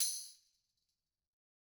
Tamb1-Hit_v2_rr2_Sum.wav